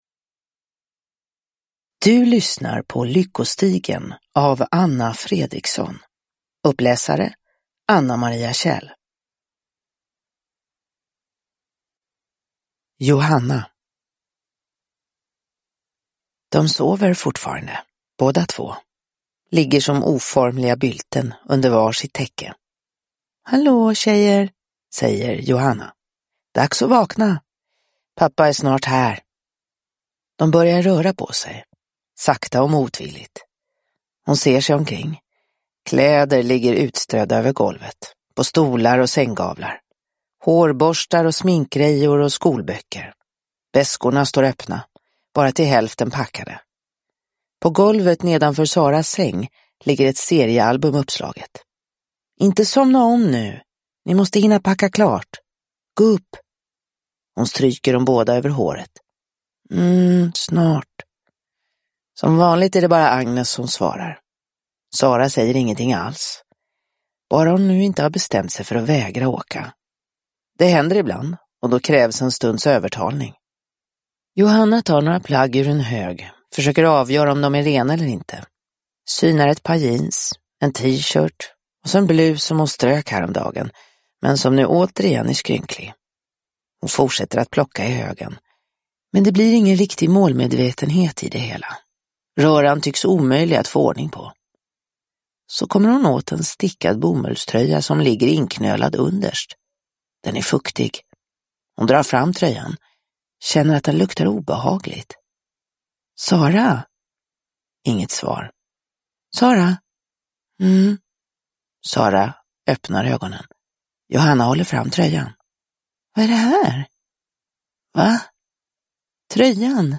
Lyckostigen / Ljudbok